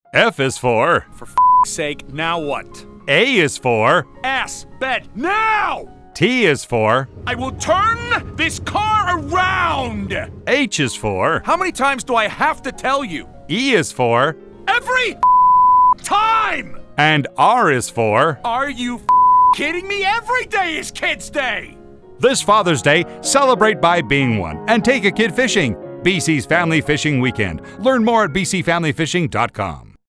Click the link below to hear Family Fishing Weekend radio spot.